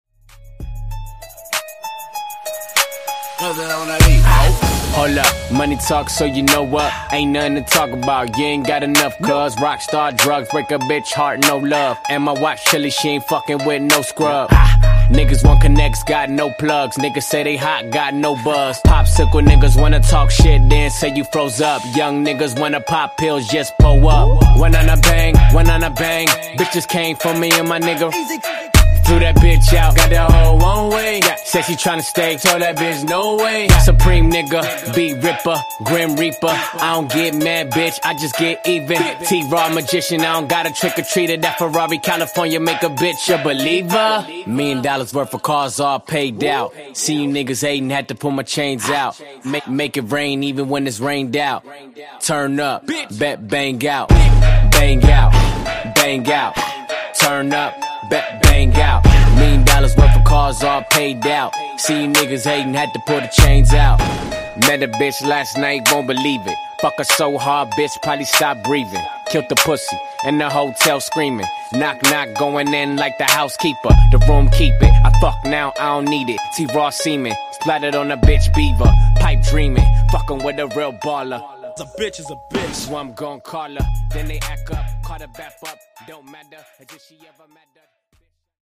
Genre: RE-DRUM Version: Dirty BPM: 88 Time